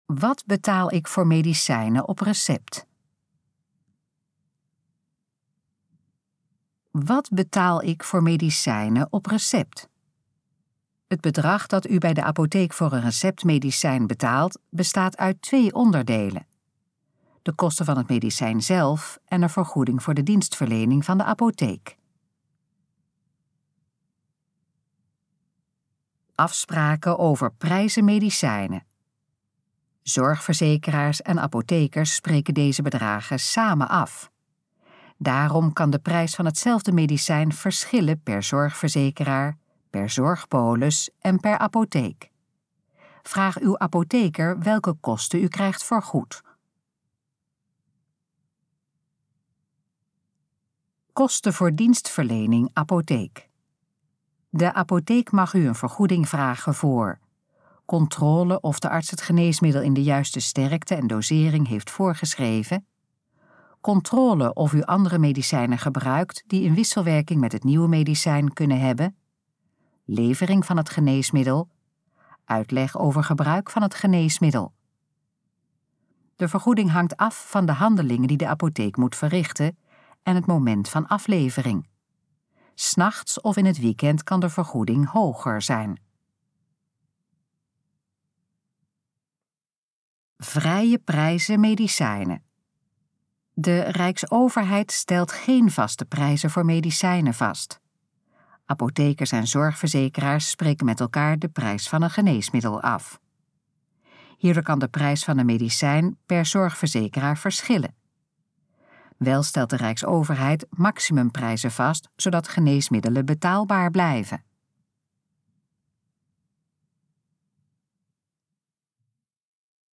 Gesproken versie van: Wat betaal ik voor medicijnen op recept?
Dit geluidsfragment is de gesproken versie van de pagina: Wat betaal ik voor medicijnen op recept?